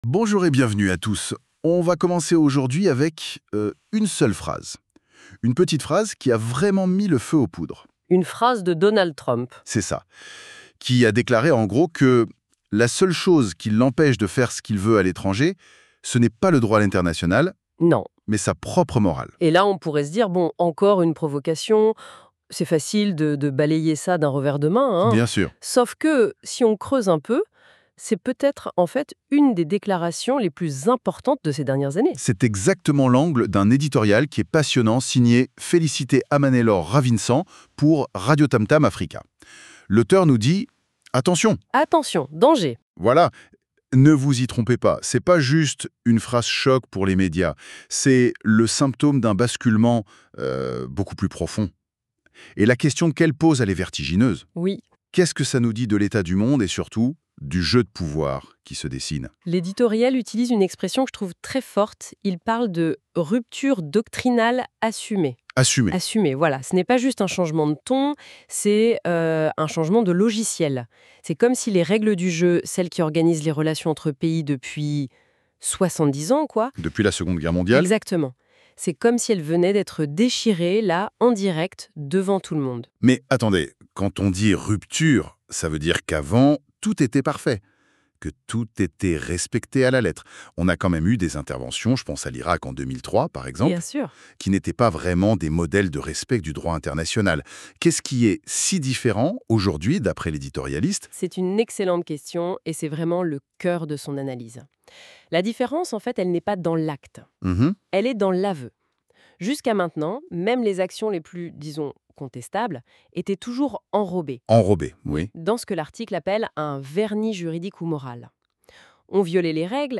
Journaliste indépendante & Éditorialiste